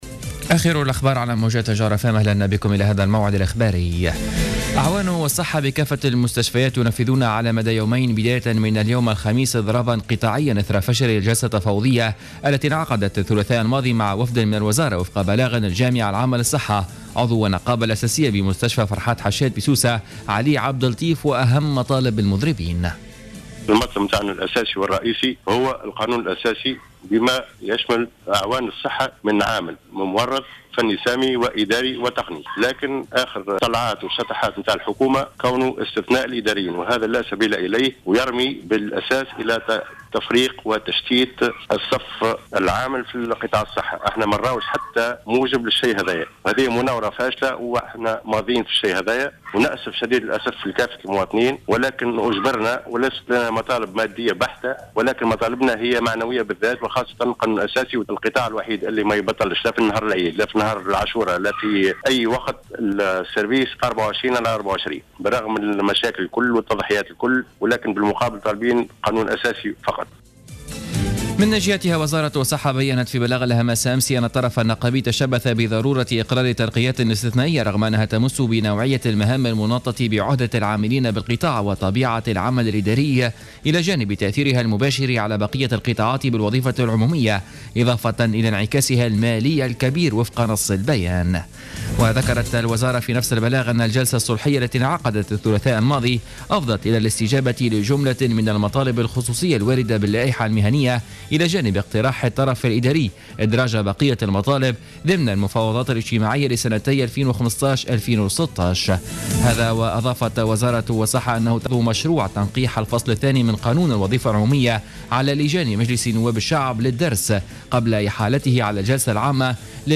نشرة أخبار منتصف الليل ليوم الخميس 11 جوان 2015